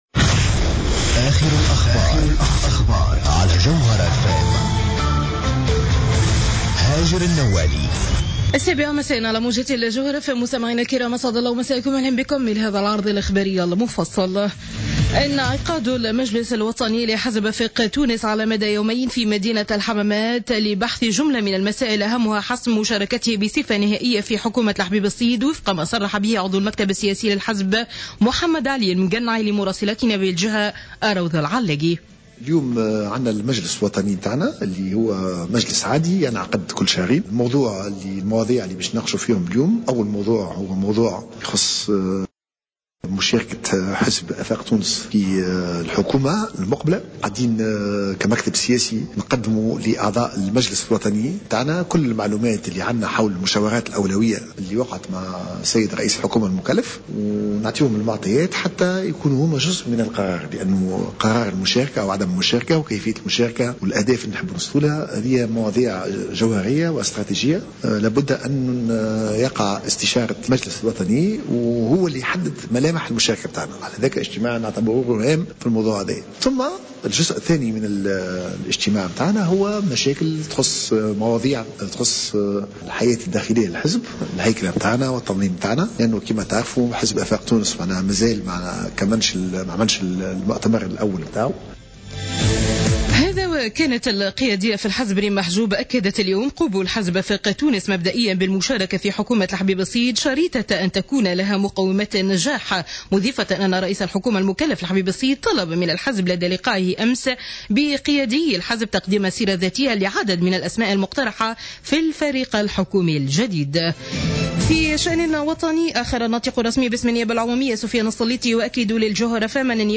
نشرة أخبار السابعة مساء ليوم السبت 17-01-15